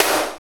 NJS SNR 9.wav